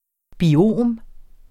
Udtale [ biˈoˀm ]